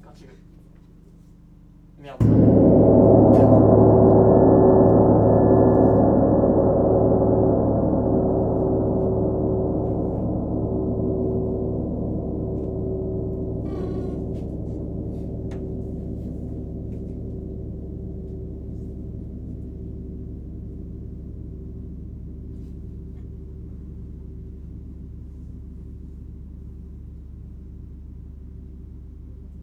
Grand_mezzoforte.wav